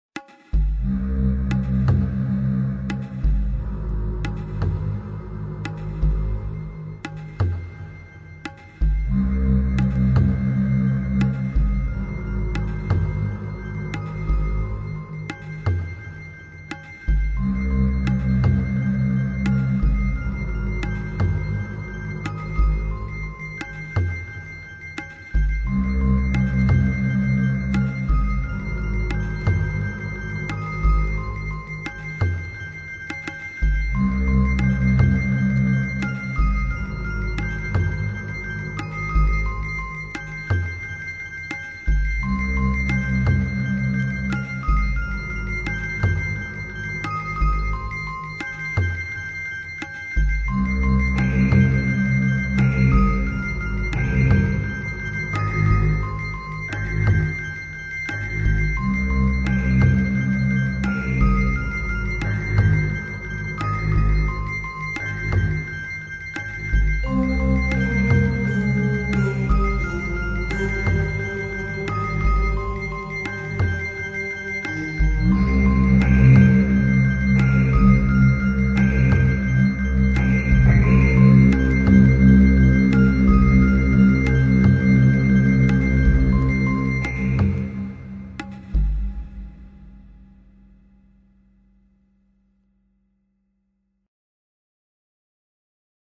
Bande originale du film